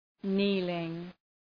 Προφορά
{‘ni:lıŋ}